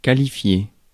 Ääntäminen
France: IPA: /ka.li.fje/